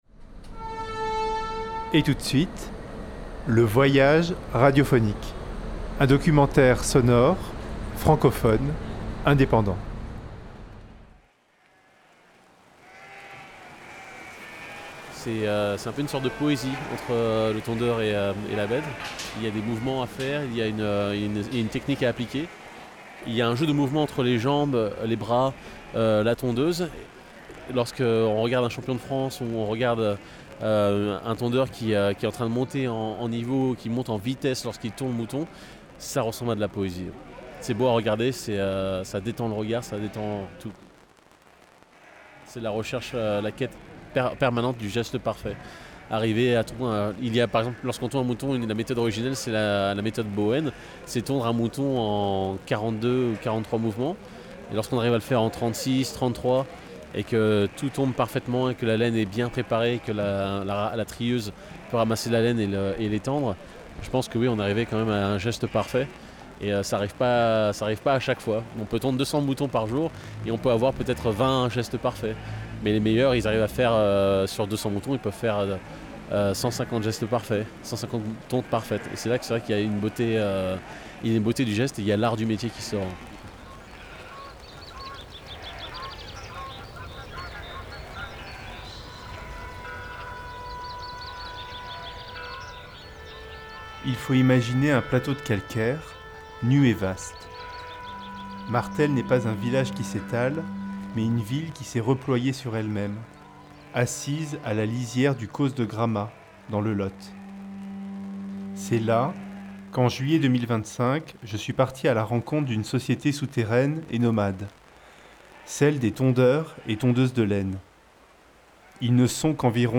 Ce documentaire lève le voile sur ce savoir-faire essentiel, s’immergeant dans deux mondes que tout oppose. D’abord, l’adrénaline des concours de tonte de Martel (Lot), où la dextérité est reine. Puis, le silence et l’intimité de la tournée solitaire automnale en Seine-et-Marne, aux côtés de l’un de ces artisans nomades.